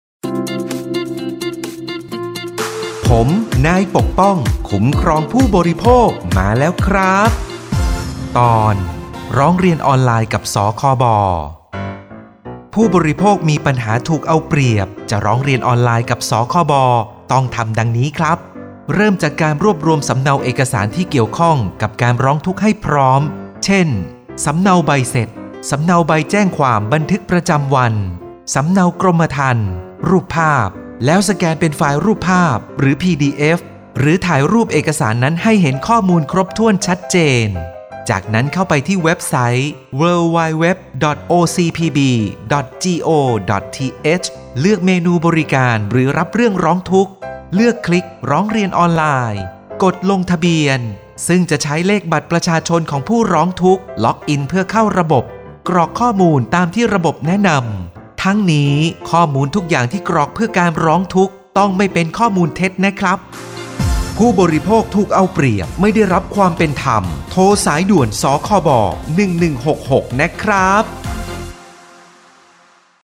สื่อประชาสัมพันธ์ MP3สปอตวิทยุ ภาคกลาง
027.สปอตวิทยุ สคบ._ภาคกลาง_เรื่องที่ 27_.mp3